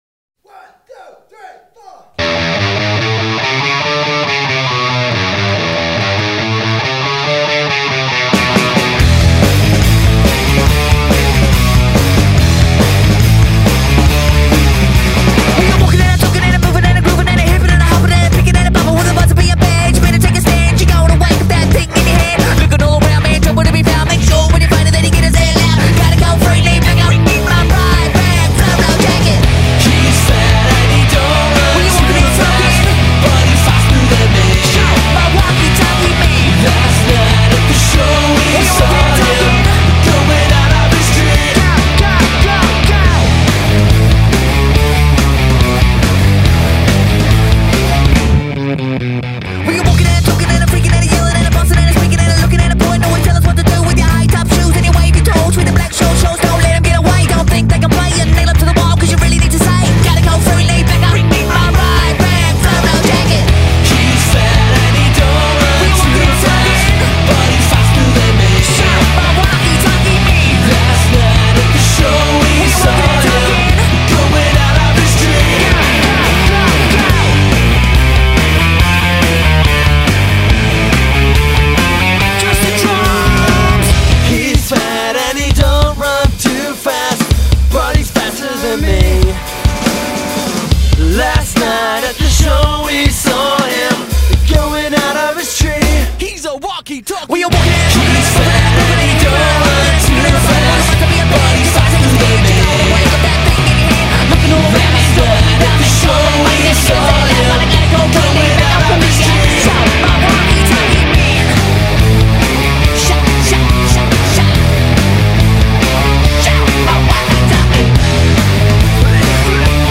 BPM141-141
Audio QualityCut From Video